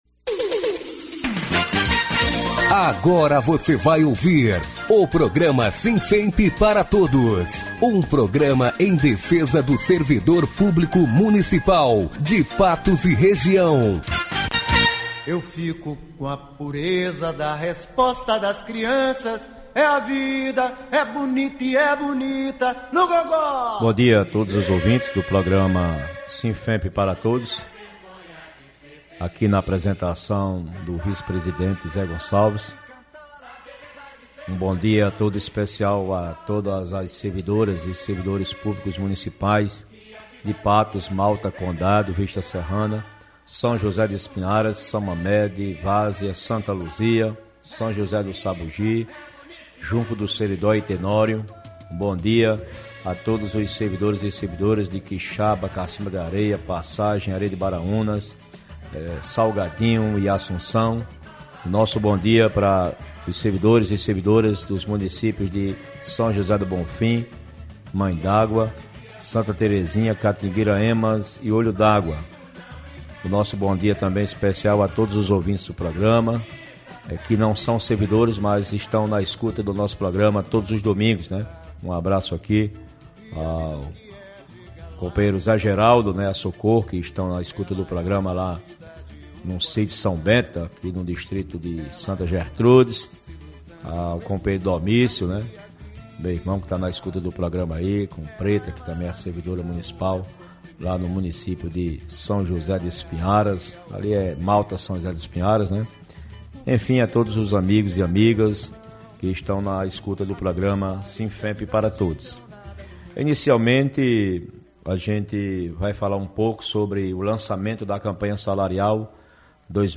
Ouça aqui o programa SINFEMP para todos, do domingo 24 de fevereiro de 2019 transmitido todo domingo na FM Espinharas, 97.9 Mhz.